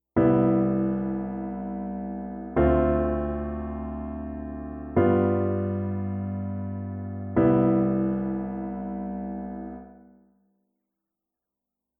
Paradiddle Kapitel 1 → Einfache Kadenz im Jazz - Musikschule »allégro«
Im Jazz wird die Einfache Kadenz mit Vierklängen gebildet. Es kommt zu jedem Dreiklang die Septime hinzu.
Schauen wir uns mal die (klanglich ausgewogenste) Terzlage an:
KadenzenJazz.mp3